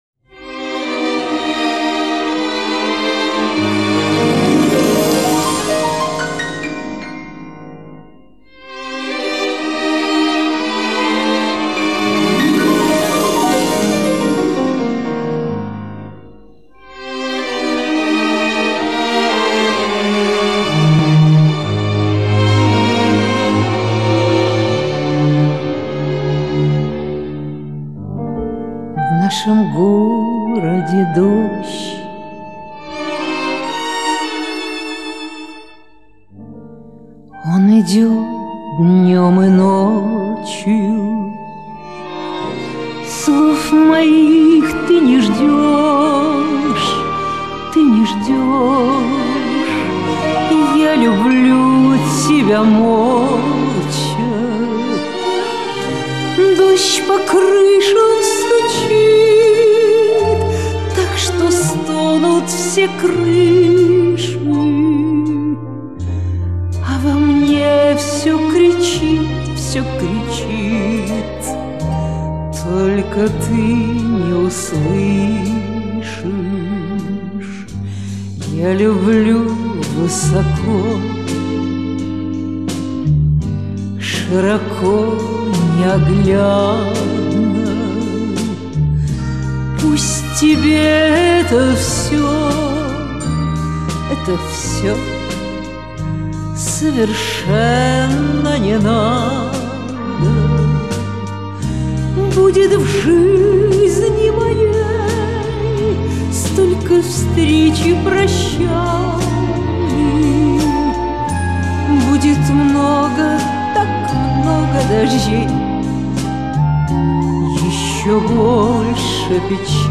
Вместо четкого ДОЖДЬ слышится ДОЩ